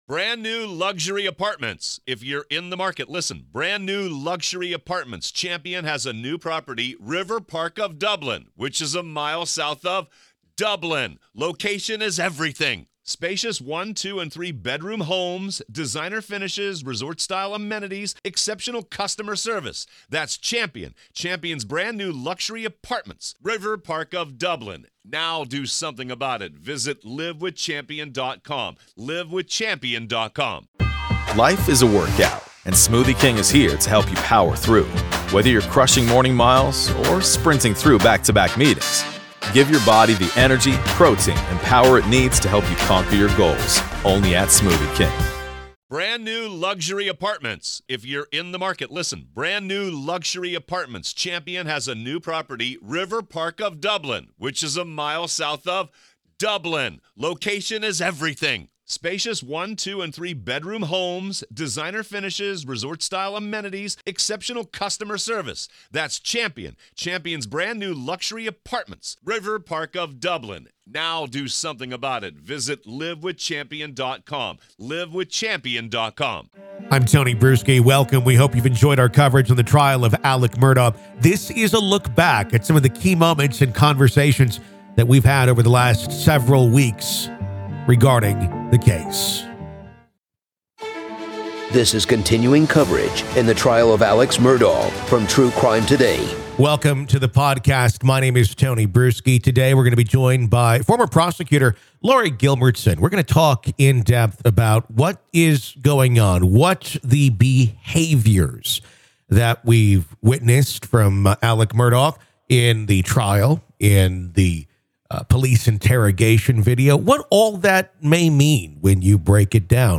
This is a retrospective look at the Alex Murdaugh case, as well as a riveting discussion that we've had with industry professionals over the course of the past few weeks.